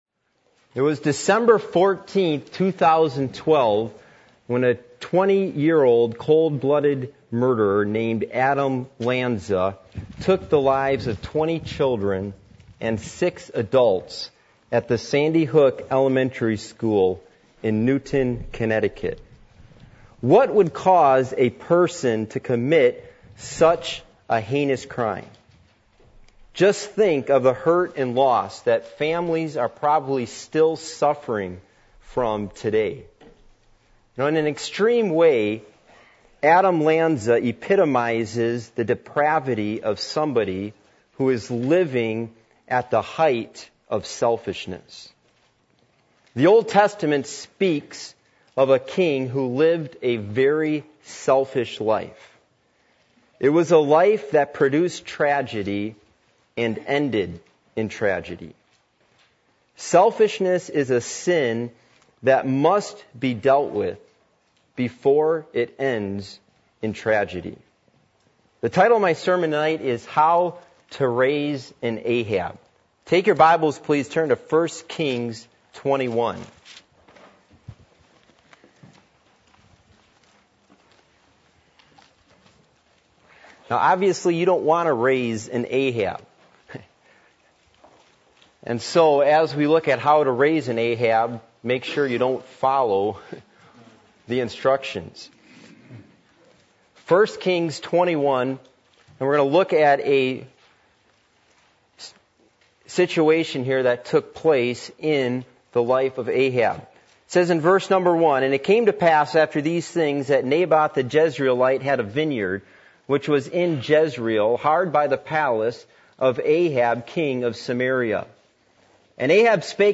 Passage: 1 Kings 21:1-16 Service Type: Midweek Meeting %todo_render% « The Principles Of Following God